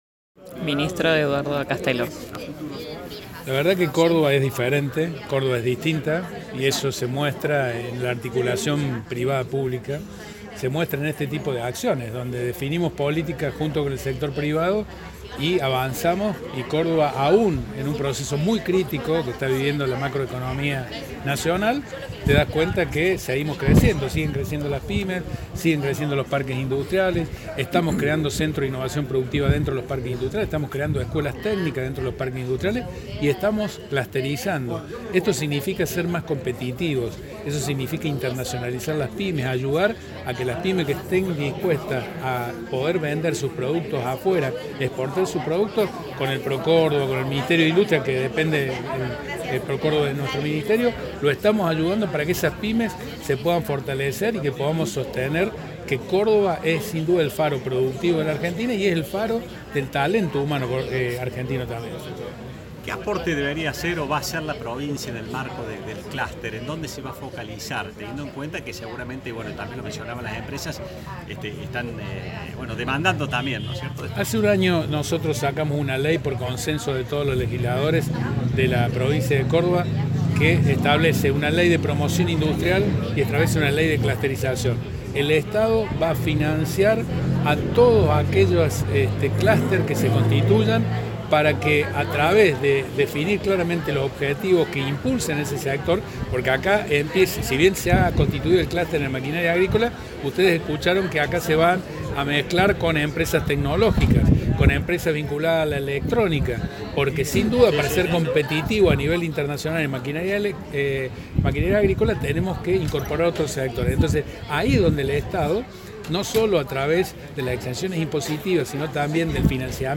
Mantuvimos una extensa pero interesantísima charla con Eduardo Accastello, actual Ministro de Industria, Comercio y Minería de la provincia de Córdoba. En base a su rol actual, nos centramos en la presentación del Clúster de la maquinaria agrícola, el primero que se crea en nuestra provincia.